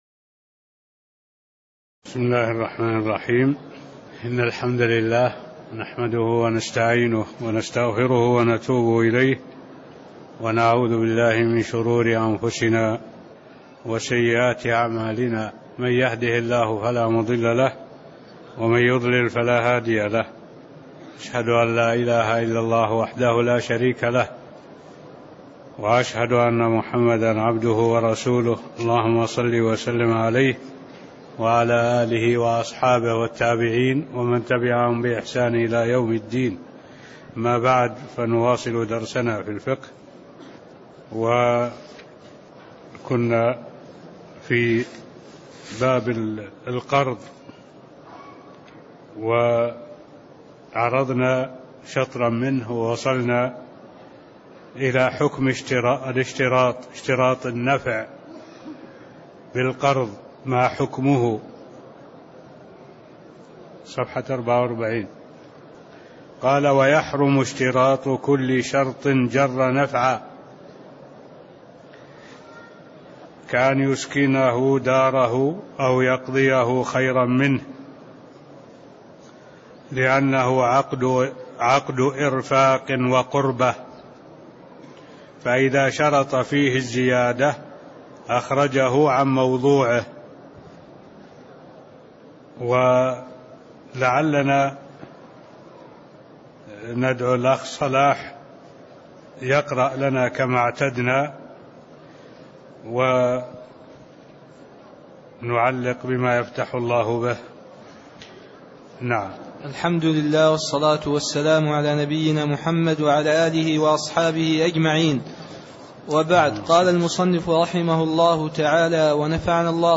المكان: المسجد النبوي الشيخ: معالي الشيخ الدكتور صالح بن عبد الله العبود معالي الشيخ الدكتور صالح بن عبد الله العبود من قوله: (حكم اشتراط النفع في القرض ماحكمه؟)